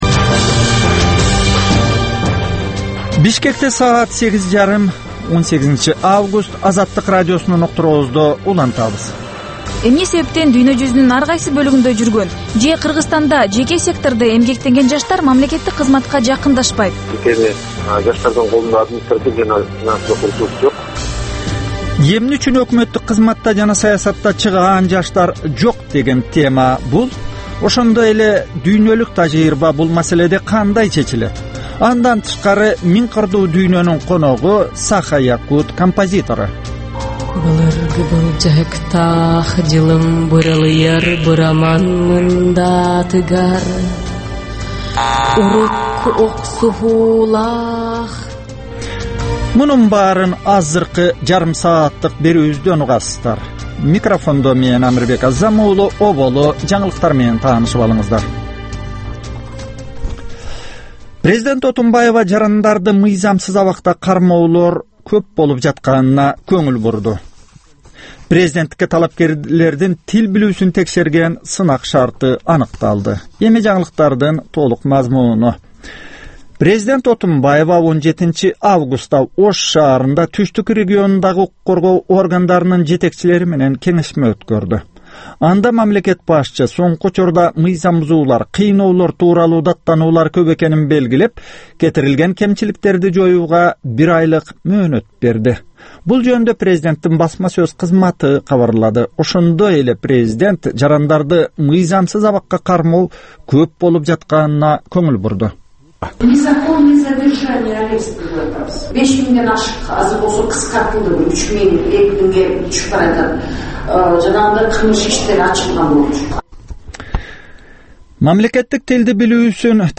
Бул таңкы үналгы берүүнүн 30 мүнөттүк кайталоосу жергиликтүү жана эл аралык кабарлар, ар кыл орчун окуялар тууралуу репортаж, маек, күндөлүк басма сөзгө баяндама, «Коом жана турмуш» түрмөгүнүн алкагындагы тегерек үстөл баарлашуусу, талкуу, аналитикалык баян, сереп жана башка берүүлөрдөн турат.